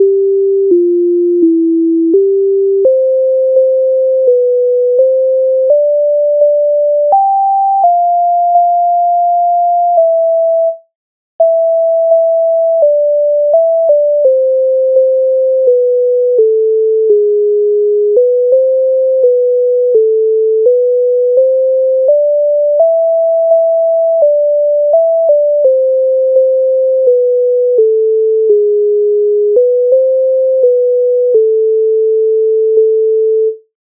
MIDI файл завантажено в тональності a-moll
При долині при охоті Українська народна пісня з обробок Леонтовича с. 131
Ukrainska_narodna_pisnia_Pry_dolyni_pry_okhoti.mp3